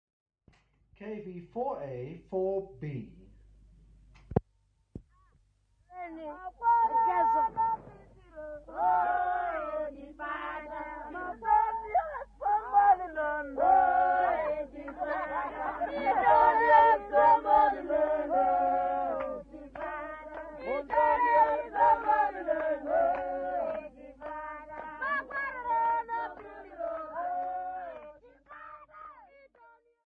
Young Venda people from Mushavhanamadi village
Folk Music
field recordings
sound recording-musical
Indigenous music